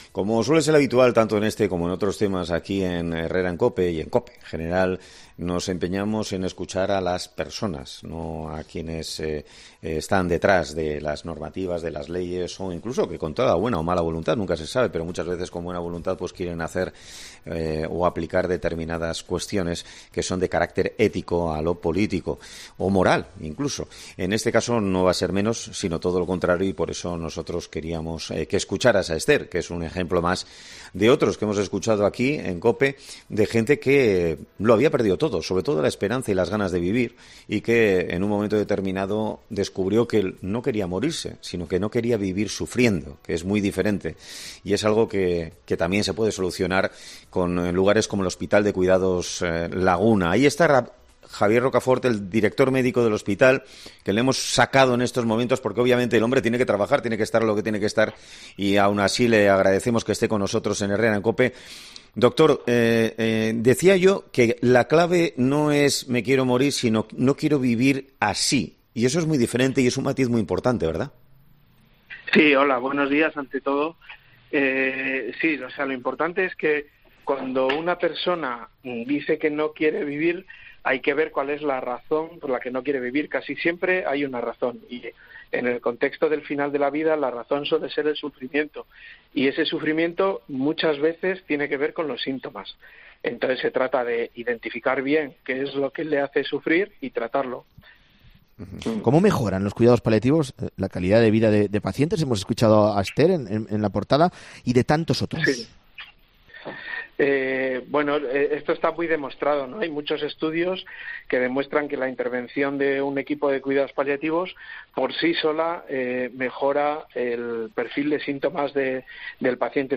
ha sido entrevistado este martes en 'Herrera en COPE'